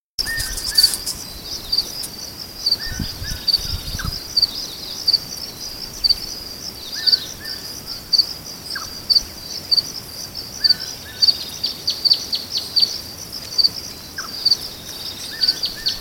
Quebracho Crested Tinamou (Eudromia formosa)
Life Stage: Adult
Location or protected area: Reserva Ecologica Los Corbalanes
Condition: Wild
Certainty: Recorded vocal